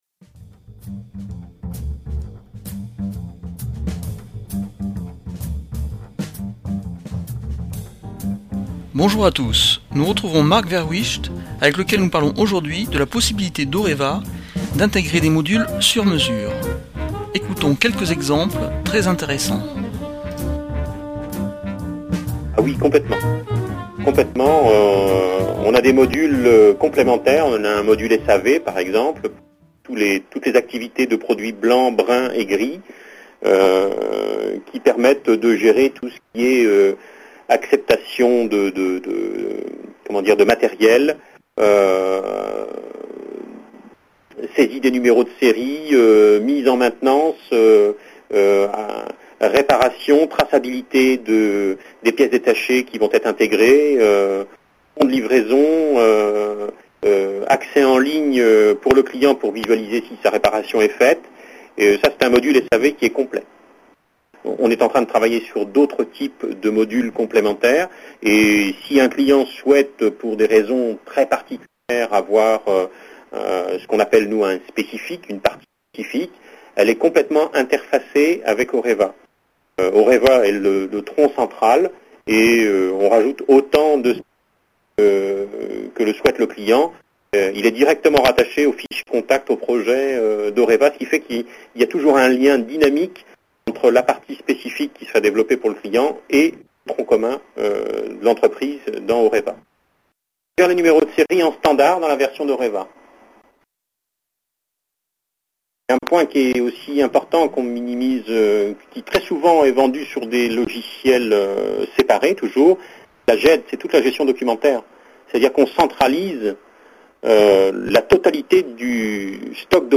Quatri�me partie de l'entretien portant sur le logiciel de gestion Oreva